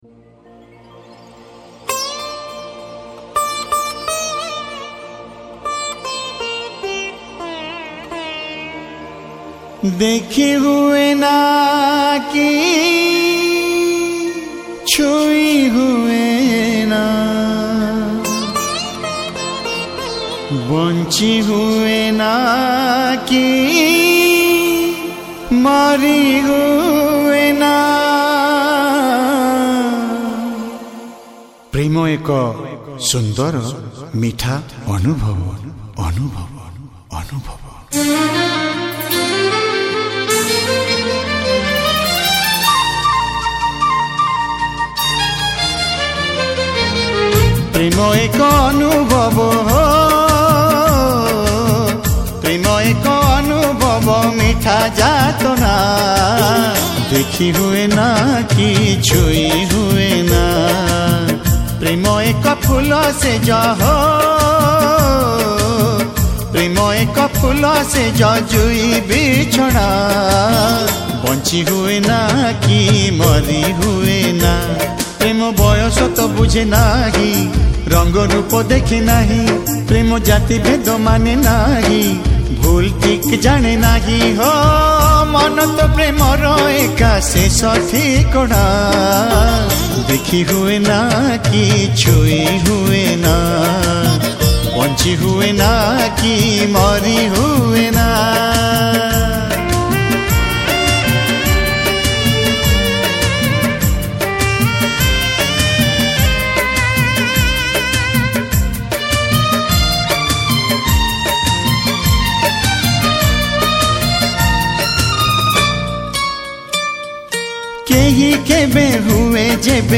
Odia Old Demand Album Sad Songs